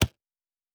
pgs/Assets/Audio/Sci-Fi Sounds/Interface/Click 6.wav at master
Click 6.wav